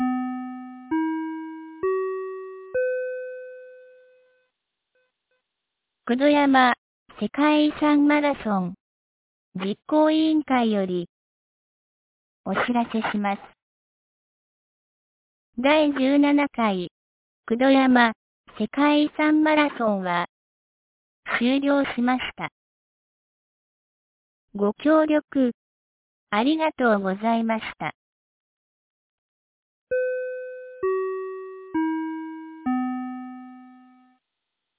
2025年01月19日 14時10分に、九度山町より全地区へ放送がありました。